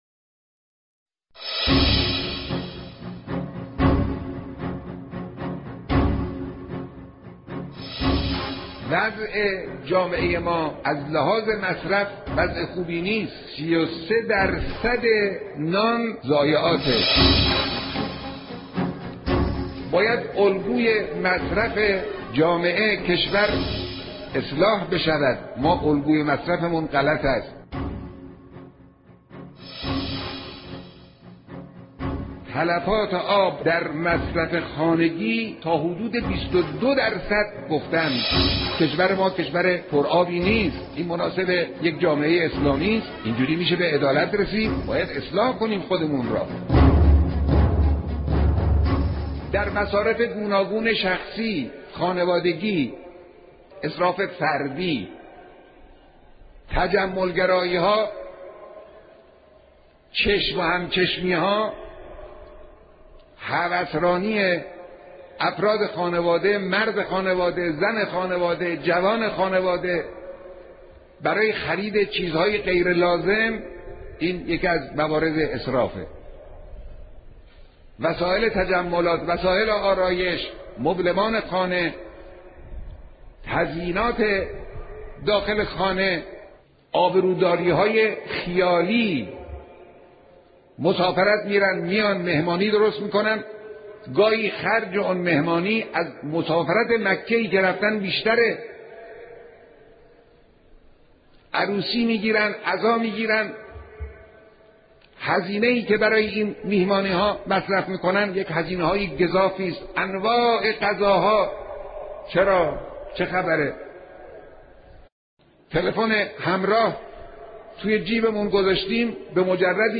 بیانات در حرم رضوی